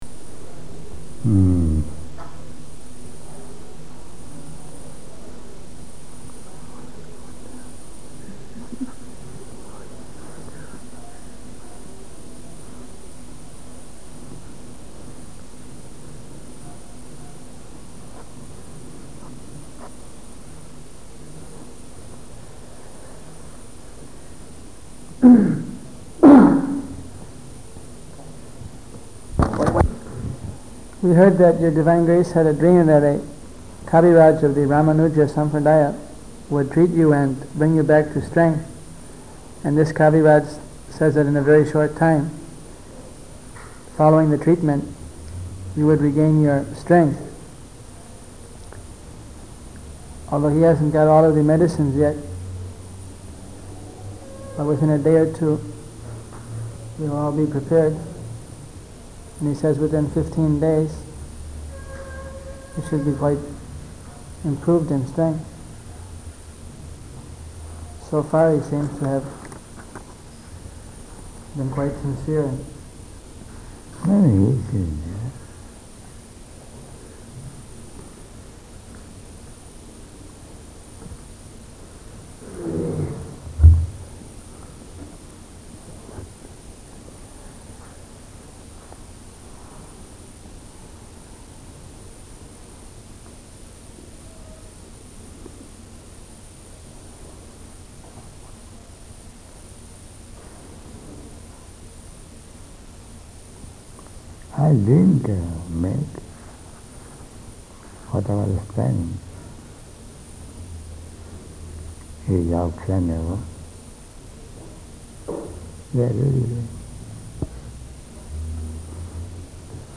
About 7 seconds into the clip there is a whisper which is currently being analyzed: ".... is going down" (that is what we hear)
Person: Giggles
Towards the end of this clip is the controversial "poison" whisper.